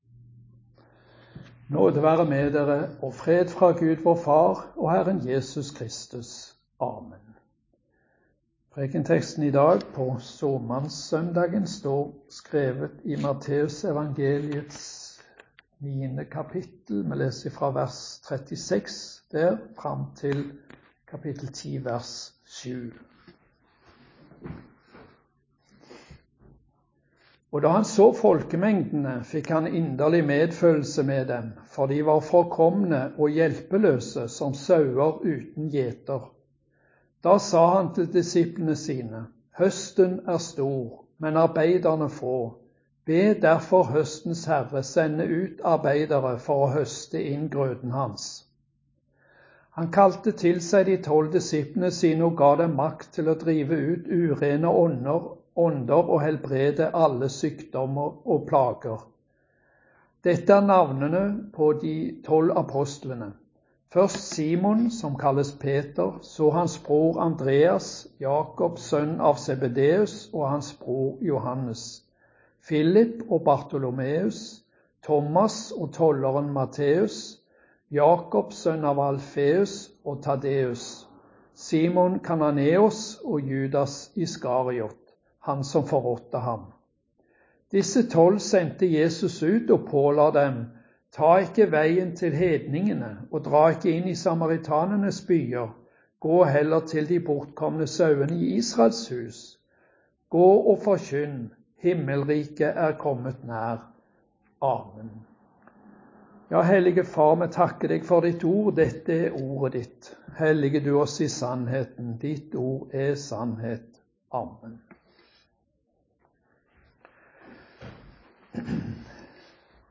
Preken-pa-Samannssondag.mp3